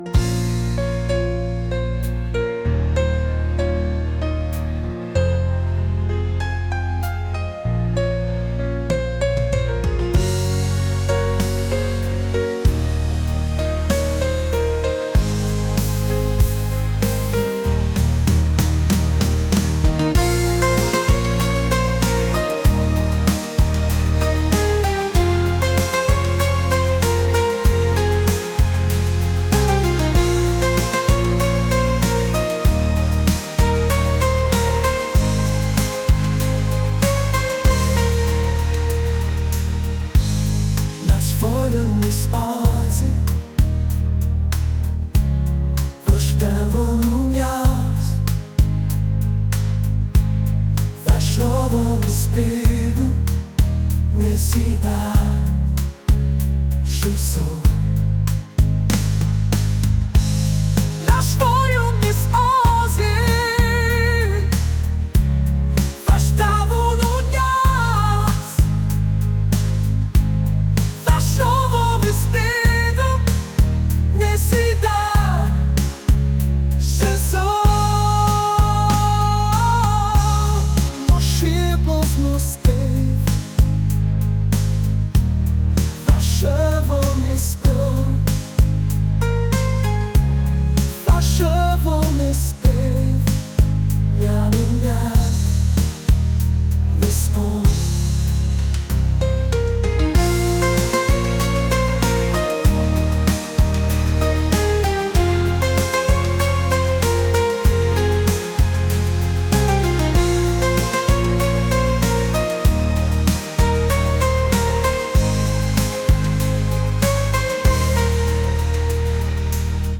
soul & rnb | pop | romantic